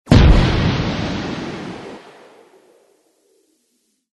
Резкие сирены, сигналы аварийной ситуации и другие звуковые эффекты помогут создать атмосферу напряжения для видео, подкастов или игровых проектов.
Шум выстрела торпеды с подлодки